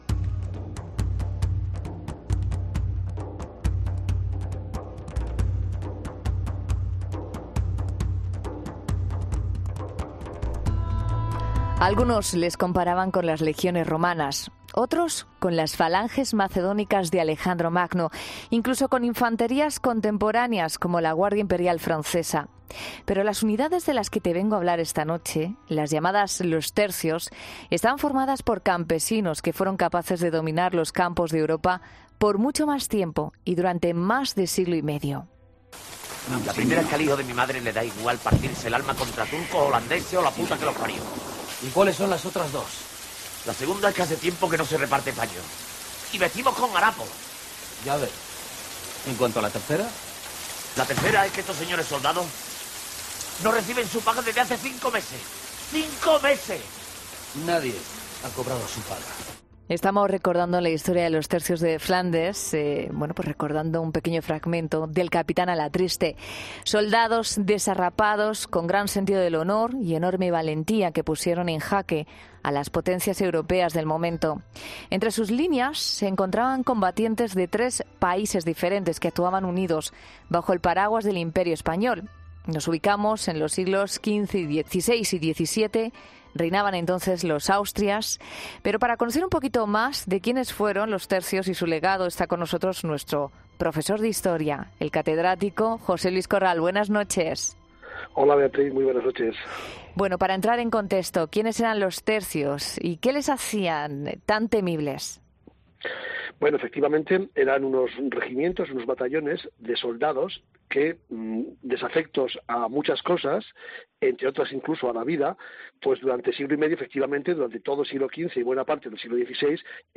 Conocemos un poco más sobre estos formidables soldados de la mano del catedrático de Historia Medieval, José Luis Corral